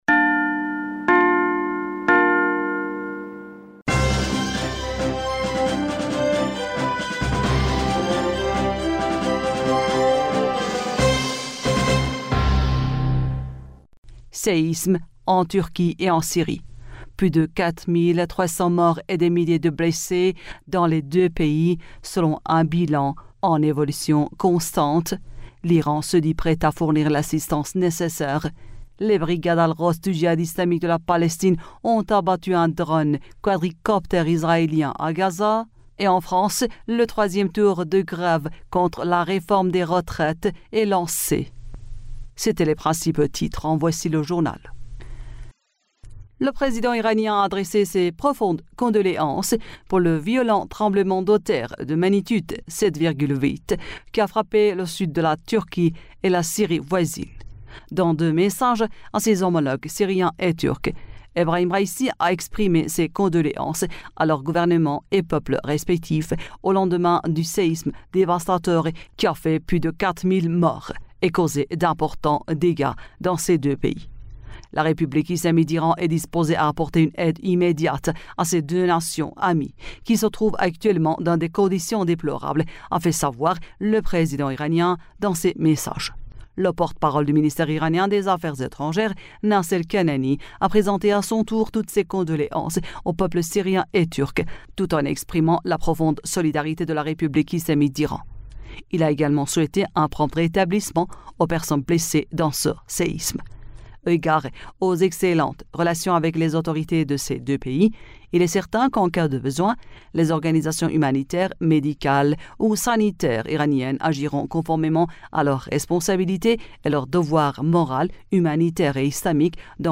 Bulletin d'information du 07 Février